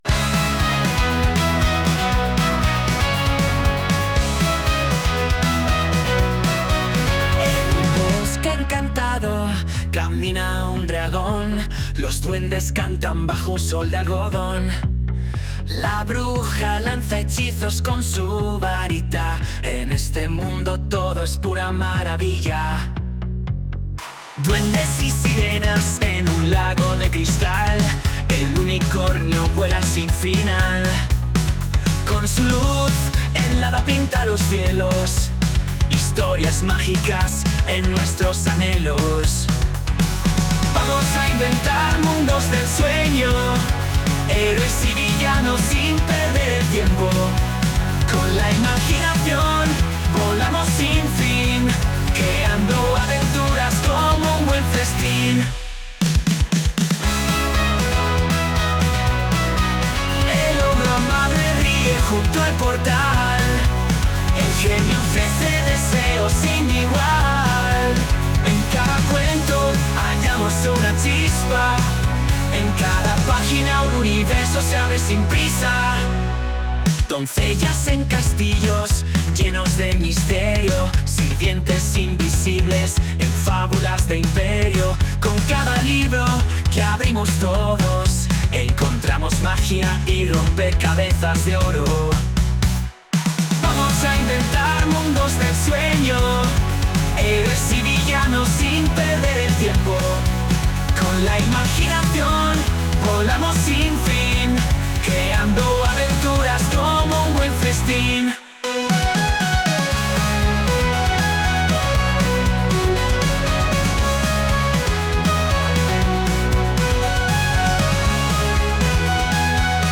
Grâce à SUNO AI, deux chansons ont été composées et elles seront diffusées par les haut-parleurs de l’école cette semaine, aux heures d’entrée et de sortie.
chanson avec ia